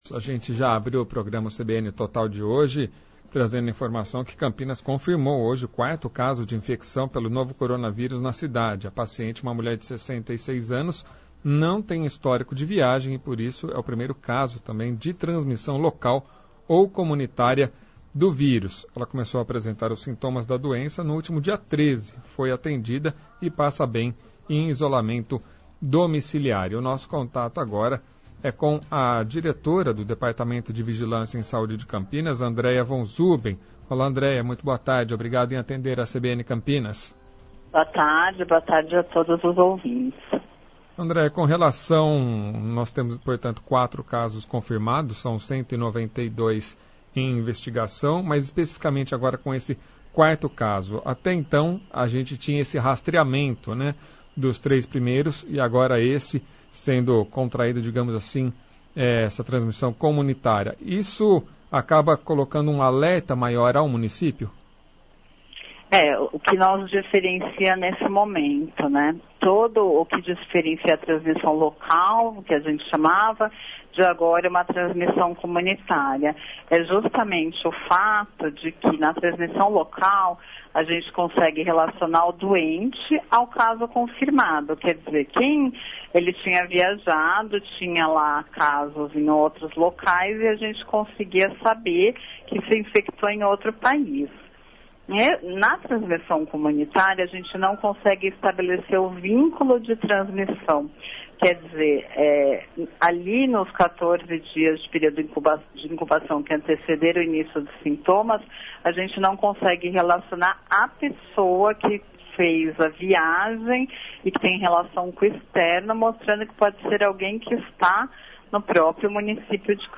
Diretora da Vigilância de Campinas, Andrea Von Zuben fala sobre os casos de Covid-19 na cidade